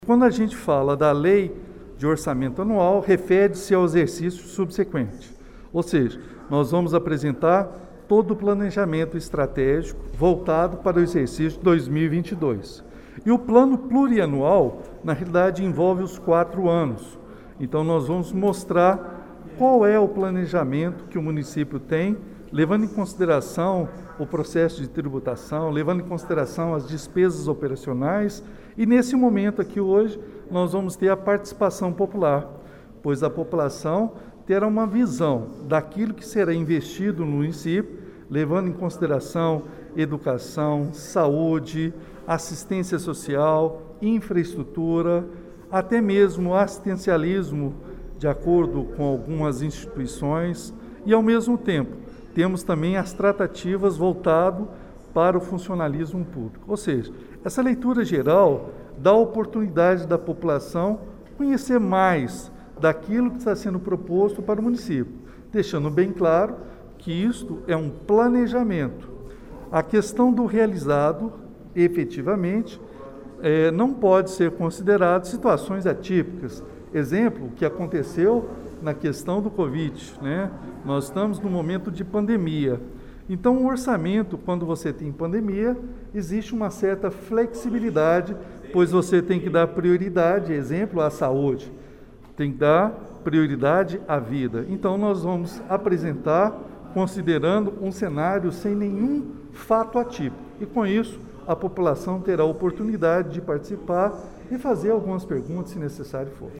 Como o Portal GRNEWS adiantou, foi realizada na noite desta segunda-feira, 22 de novembro, logo após a reunião ordinária dos vereadores paraminenses, uma audiência pública para discutir o orçamento do Município para 2022.
Mesmo já tendo realizado uma audiência pública sobre este mesmo assunto no final de agosto, o prefeito Elias Diniz (PSD) esteve na Câmara Municipal após o pedido dos vereadores e esclareceu sobre os principais pontos do planejamento: